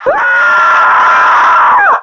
NPC_Creatures_Vocalisations_Robothead [99].wav